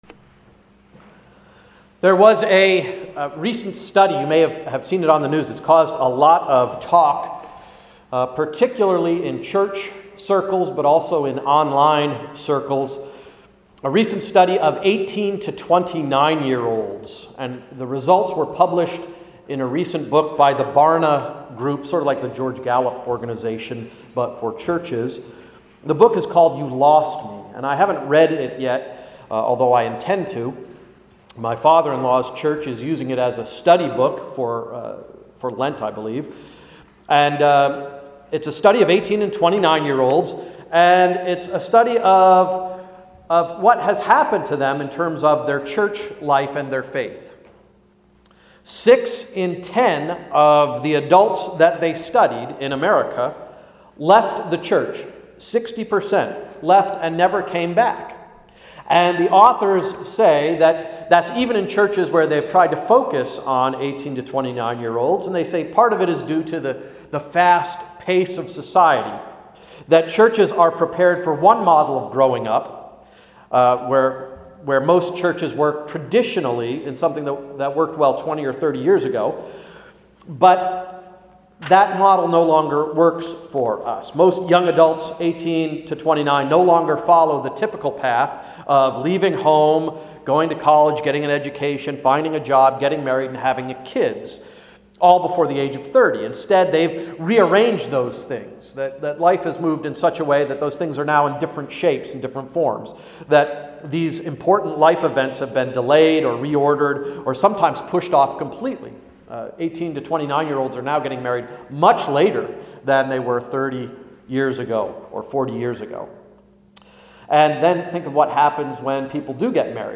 Sermon of February 19th–“Fly the Friendly Skies”